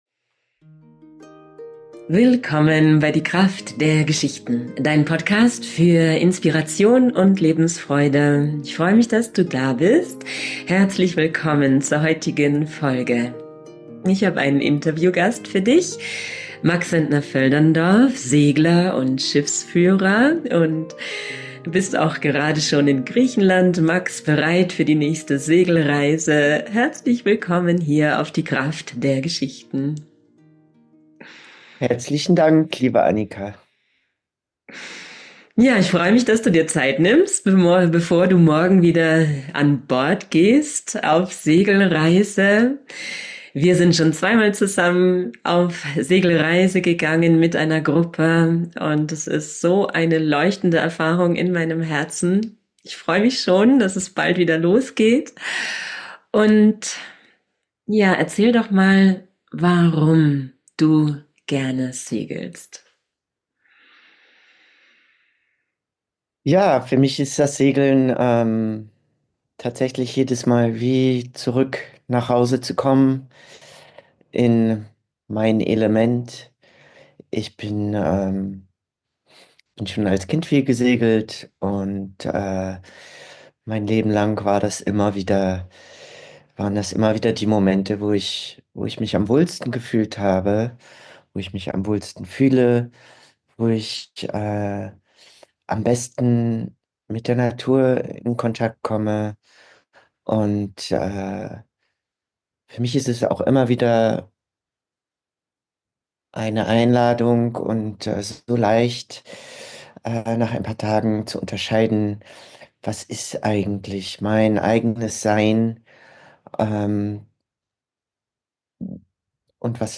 Ganz viel Freude mit diesem Interview wünsche ich Dir.